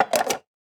Cup Stack Plastic Sound
household